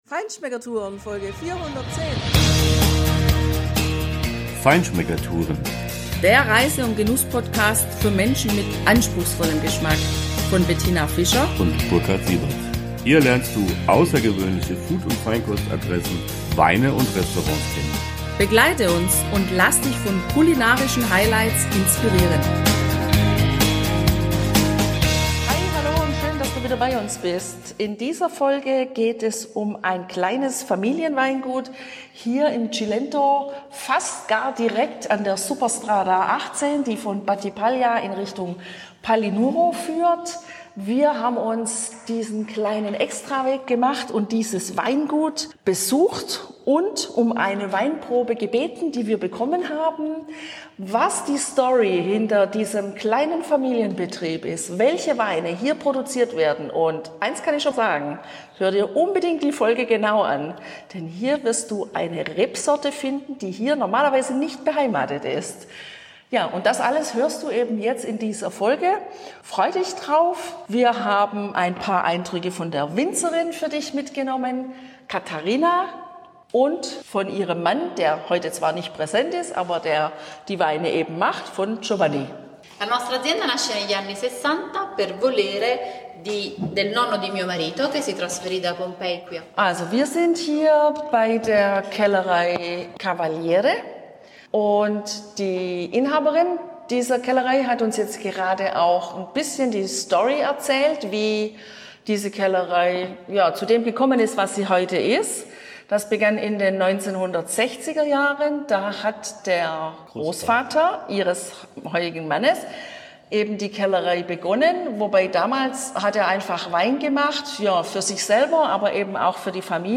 Du begleitest uns zu einem kleinen Familienweingut, wo Gastfreundschaft, Leidenschaft und Weinhandwerk seit den 1960er Jahren gelebt werden.